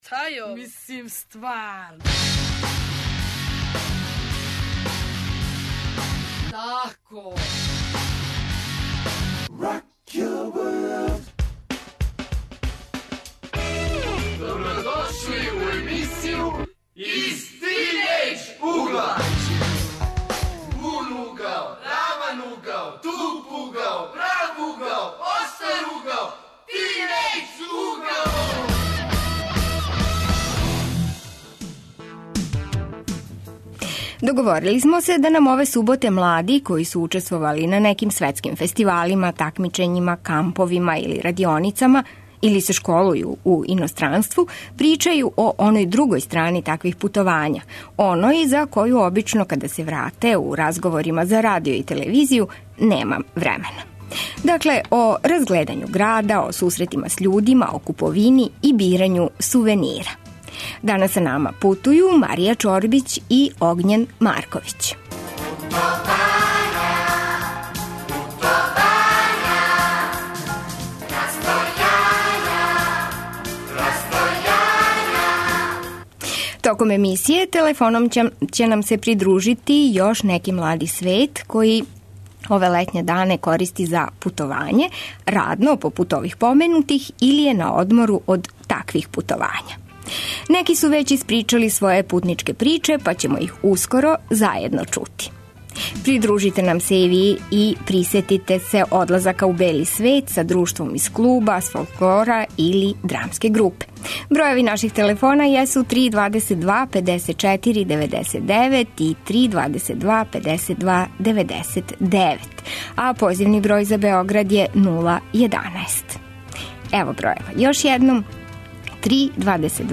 Гости тинејџери који су ишли на таква путовања и они ће нам пренети своје утиске.